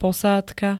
Zvukové nahrávky niektorých slov
rnnh-posadka.ogg